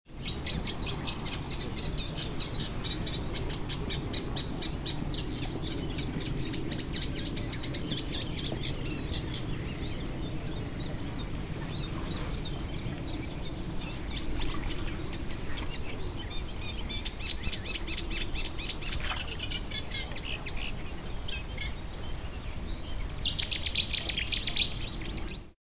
South Somerset RSPB group organised a walk round Ham Wall on 17 May 2014.
The sounds (all in stereo, mp3 files):-
Reed Warbler (with a Cetti's Warbler at the end)
HamWall_Reed_Warbler_STE-021.mp3